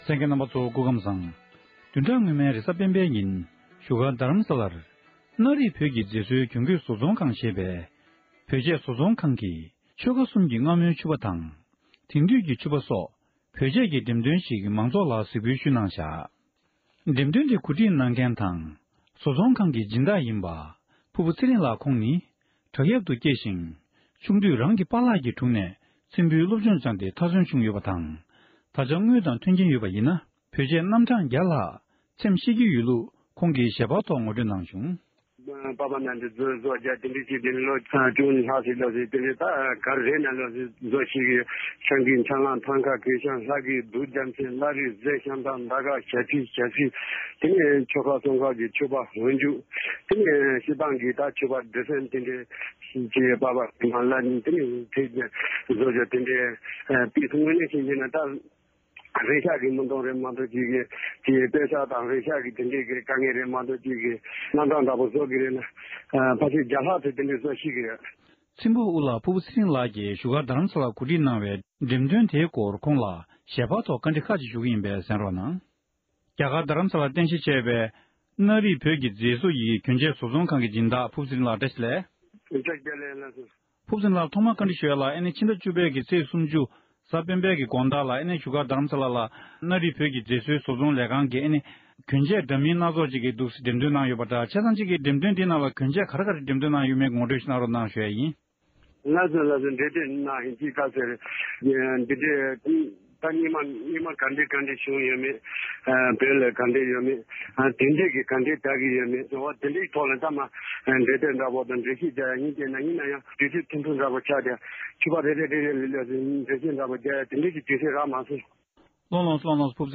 བཀའ་འདྲི་ཞུས་པར་གསན་རོགས༎